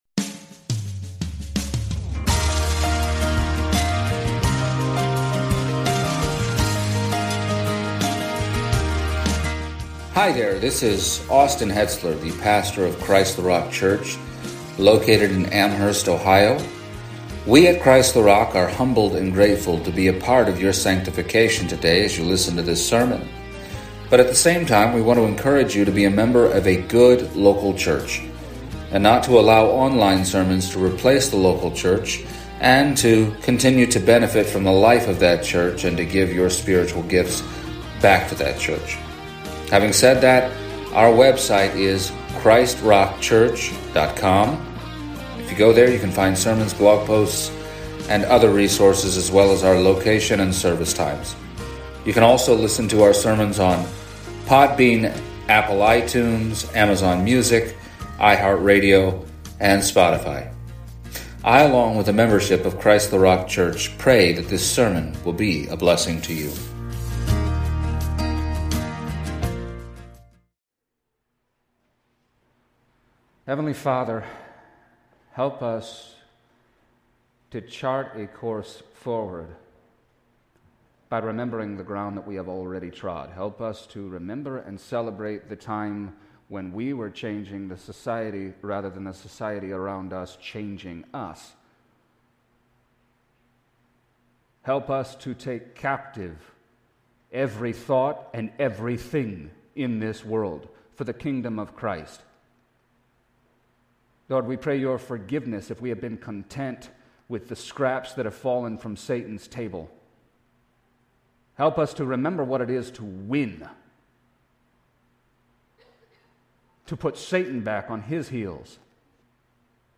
Passage: Acts 19:8-10 Service Type: Sunday Morning